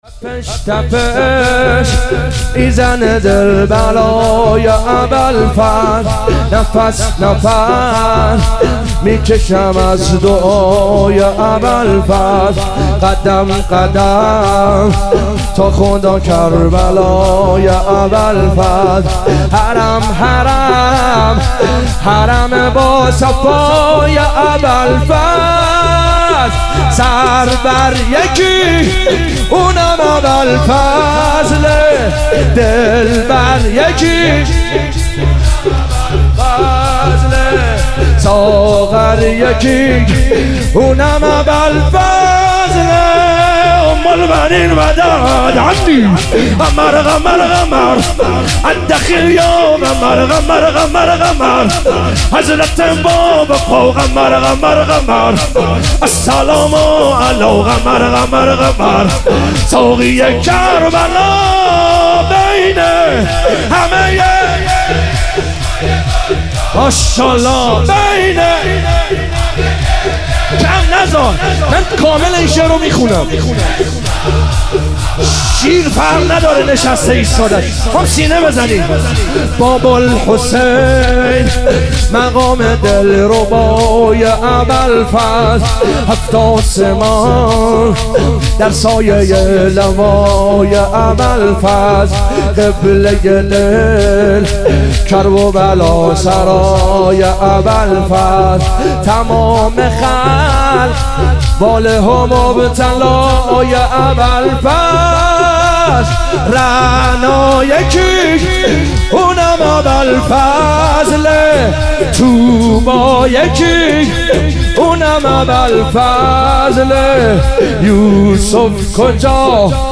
ظهور وجود مقدس حضرت زینب علیها سلام - شور